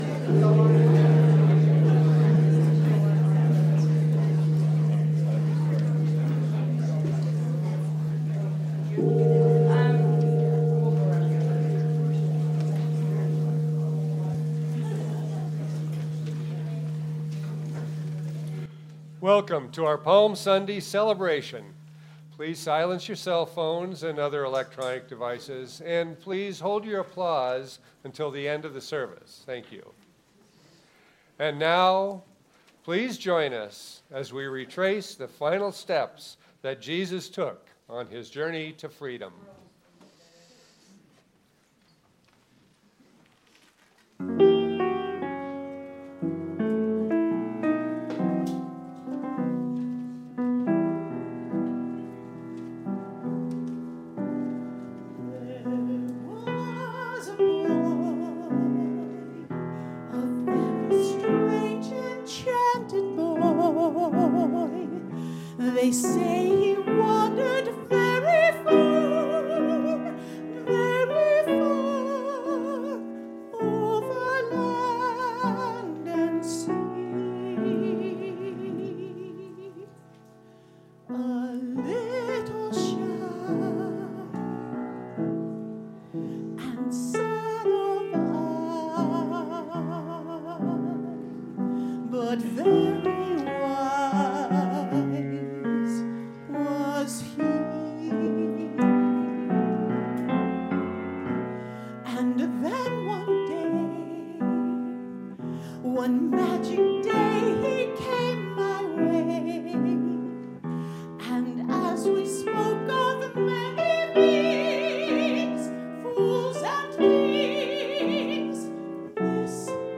The audio recording (below the video clip) also captures the complete service excluding a 10-minute prelude.
This immersive experience retraces the final steps of Jesus on his path to liberation, brought to life by a talented ensemble of musicians and singers